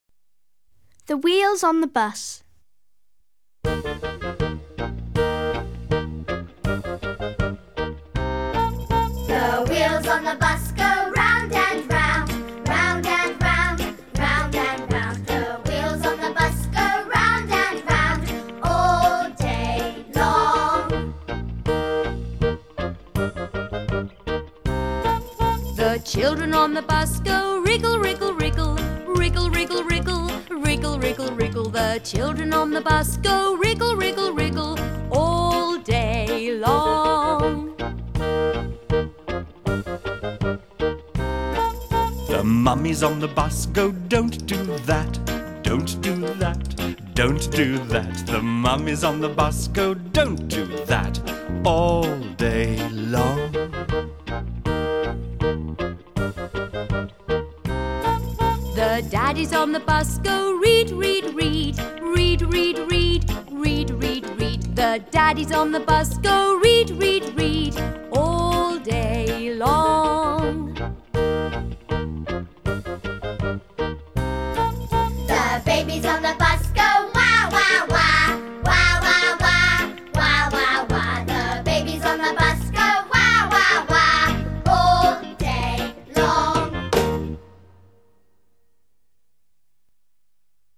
Фестиваль "День английского языка"
Песня "Колеса автобуса" [1] (5 класс)